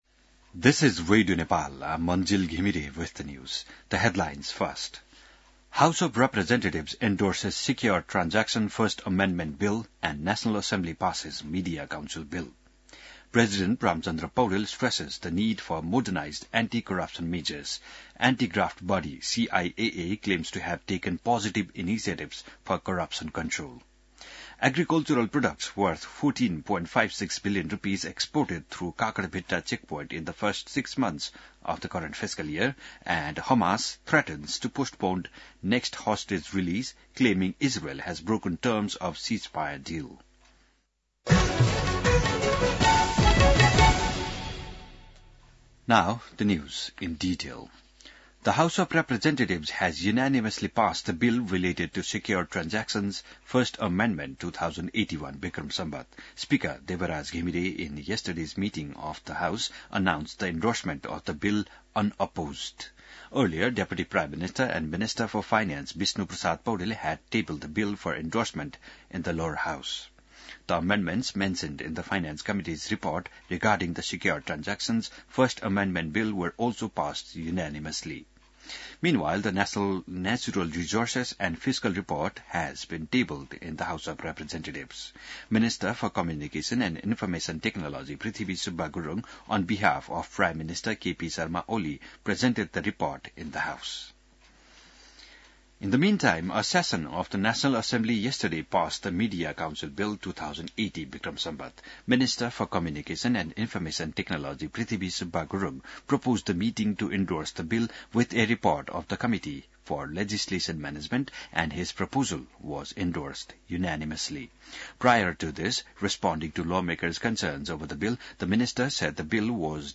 An online outlet of Nepal's national radio broadcaster
बिहान ८ बजेको अङ्ग्रेजी समाचार : ३० माघ , २०८१